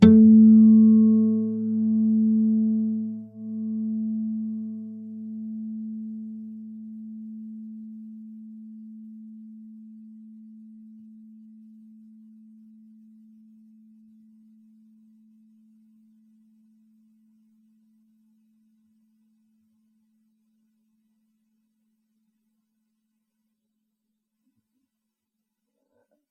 Nylon Guitar Single notes. » Clean A str pick
描述：Single note picked A (5th) string.
标签： acoustic guitar nylonguitar singlenotes
声道立体声